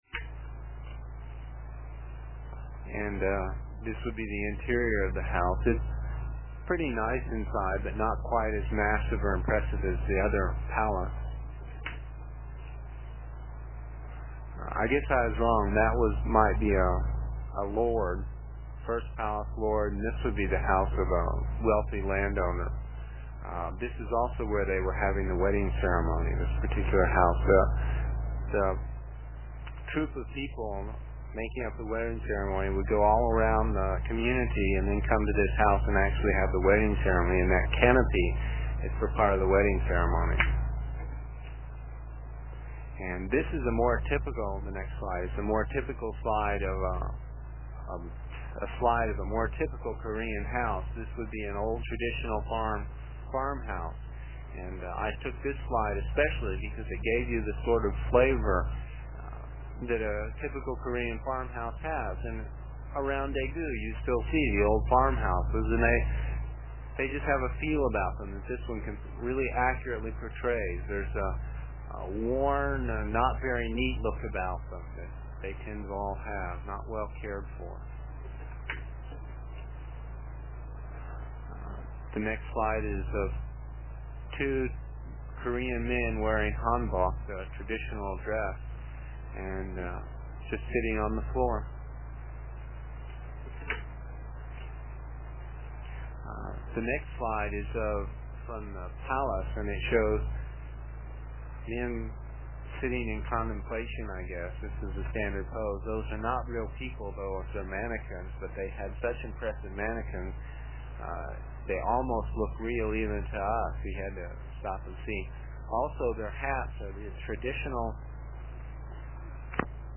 It is from the cassette tapes we made almost thirty years ago. I was pretty long winded (no rehearsals or editting and tapes were cheap) and the section for this page is about seven minutes and will take about three minutes to download with a dial up connection.